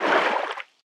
File:Sfx creature seamonkeybaby swim fast 05.ogg - Subnautica Wiki
Sfx_creature_seamonkeybaby_swim_fast_05.ogg